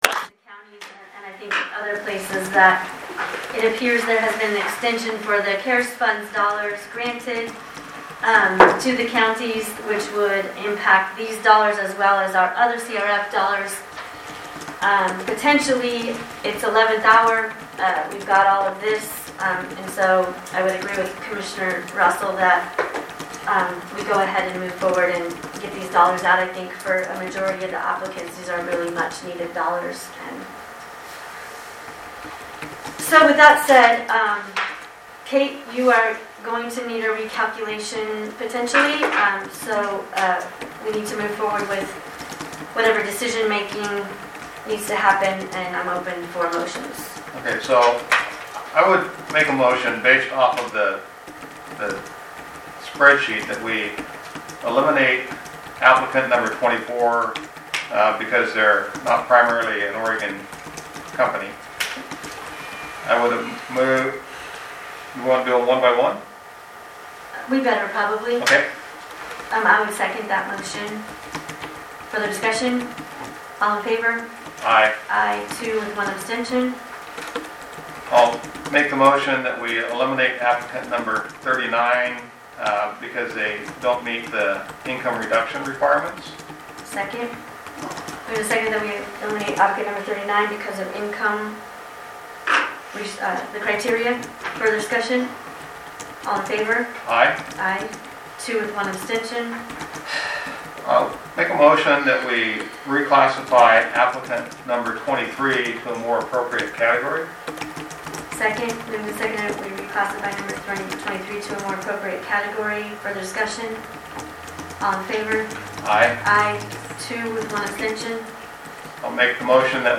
Board of Commissioners Meeting | Morrow County Oregon
12-30-20_boc_meeting_part_1_of_2.mp3